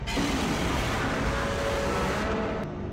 File:G-1.0 Initial Form Roar.mp3
train whistle